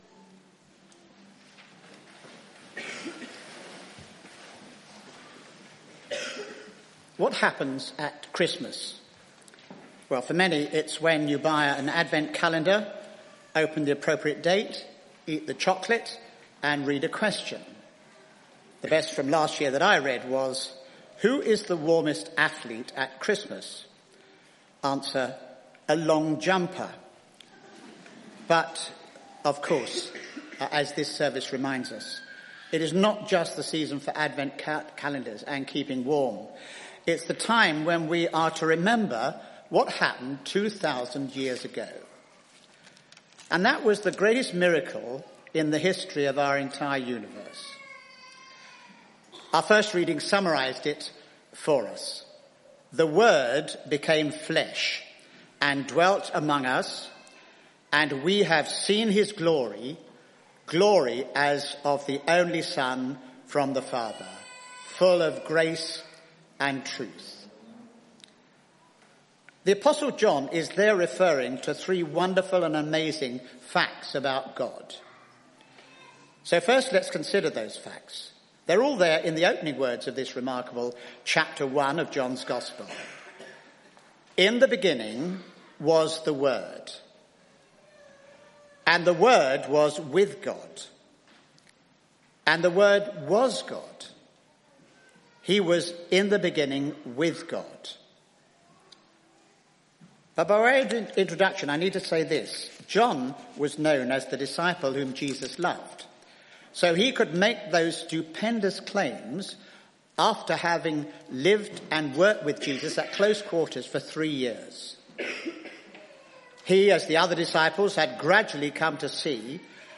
jpc_carols_sermon_2018.mp3